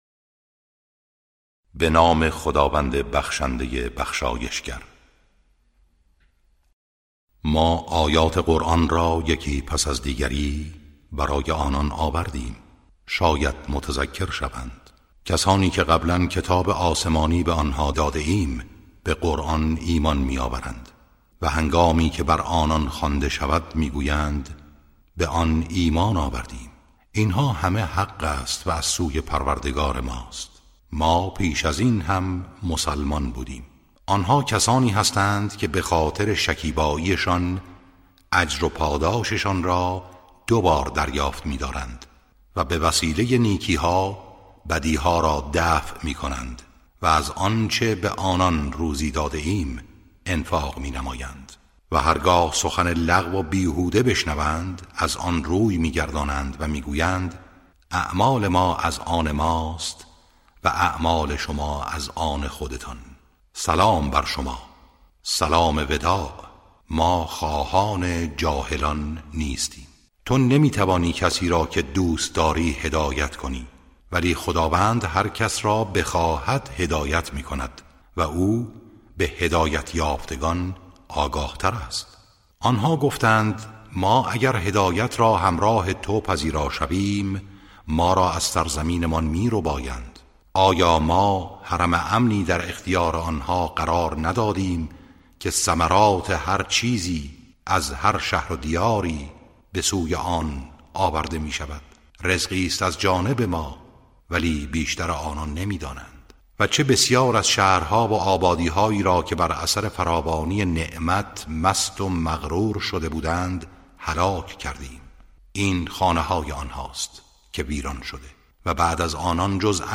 ترتیل صفحه ۳۹۲ از سوره قصص(جزء بیستم)